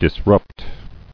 [dis·rupt]